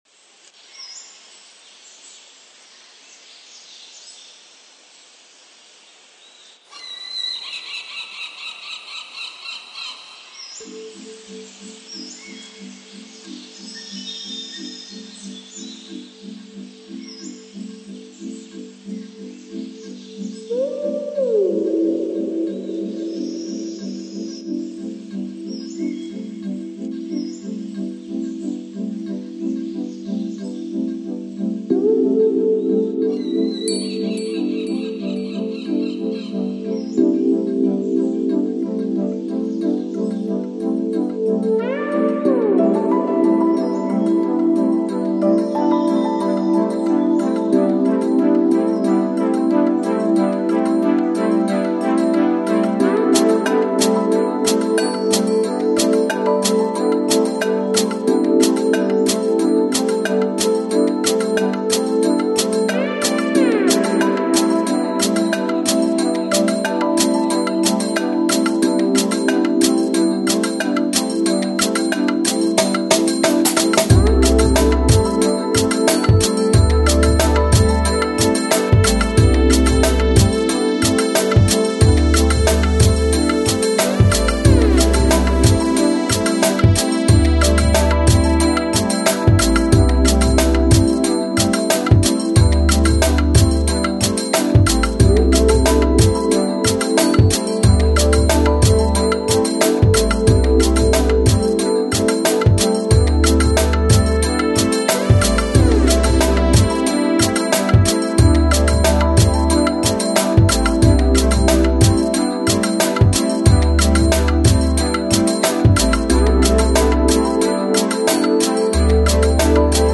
Lounge, Chill Out, Downtempo Год издания